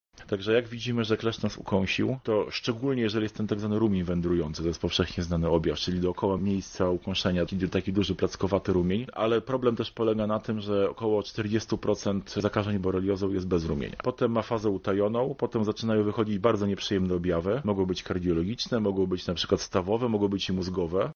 Więcej o tym, jak wpływa na nasze zdrowie ukąszenie kleszcza, mówi entomolog profesor